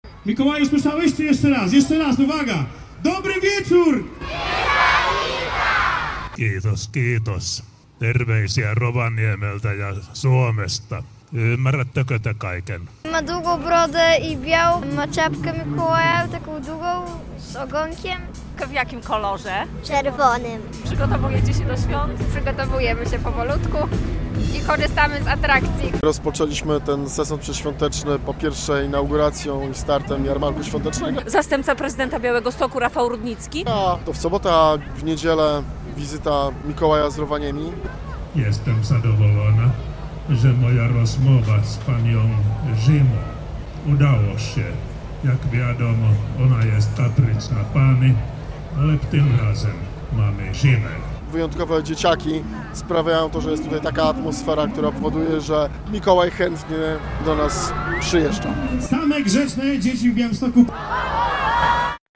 Do Białegostoku przyjechał Mikołaj z Rovaniemi - relacja